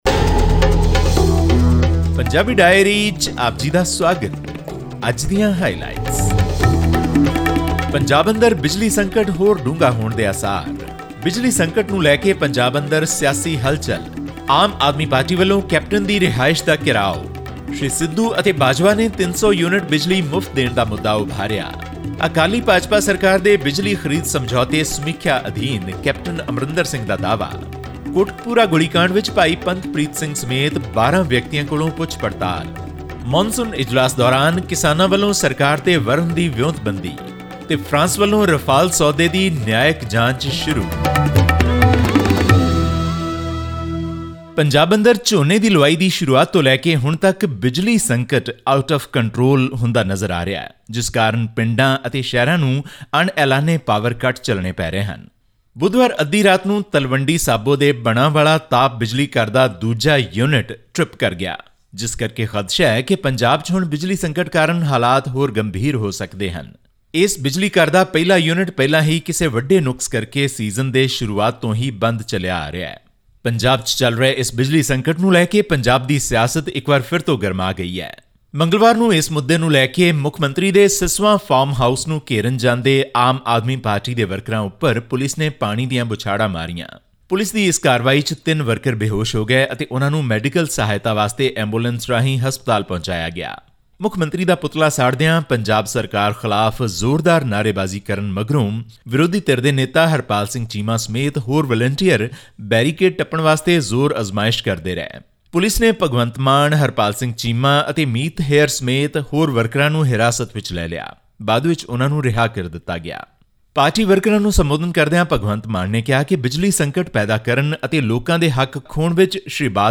Aam Aadmi Party (AAP) workers on 3 July staged a protest outside Punjab Chief Minister Captain Amarinder Singh’s residence in Mohali over the issue of unprecedented electricity shortage in the state. This and more in our weekly news bulletin from Punjab.